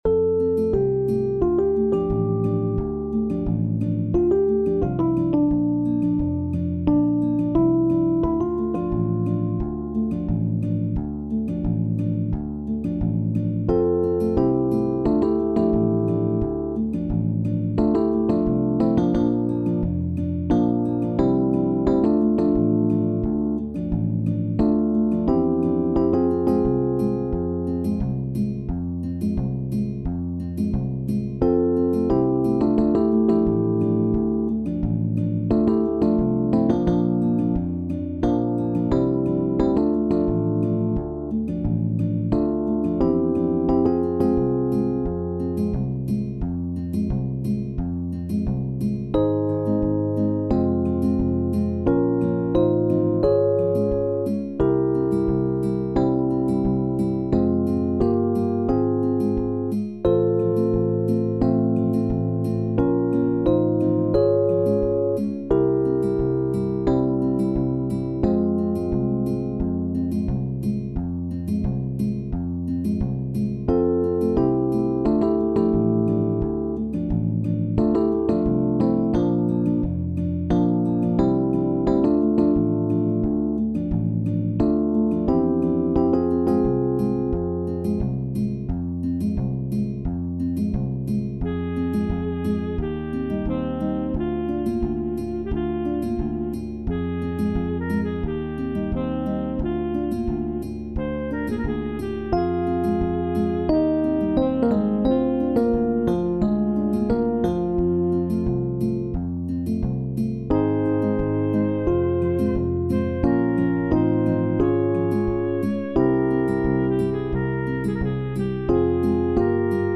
SATB | SSATTB | SSA met piano | SSAATB | SSSAAB
Een prachtig lied met getokkelde akkoorden